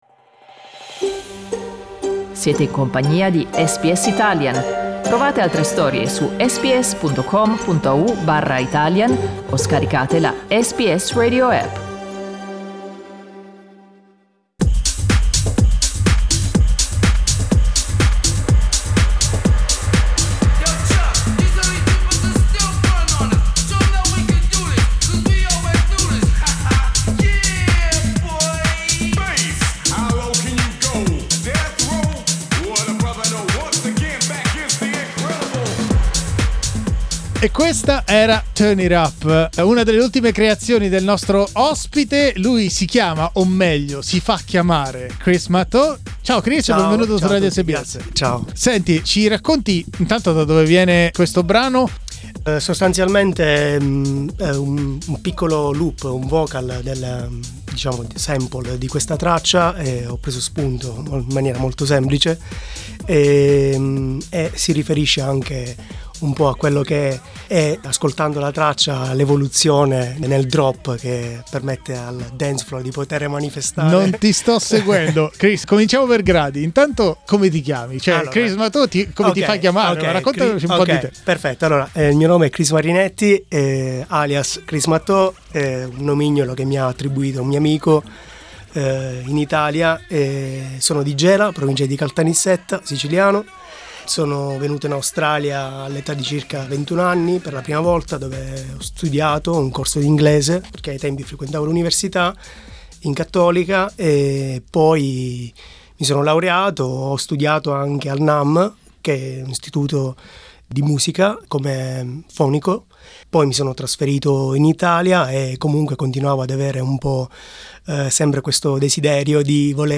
Da Milano a Melbourne al ritmo della musica elettronica sperimentale, un italiano racconta la sua storia.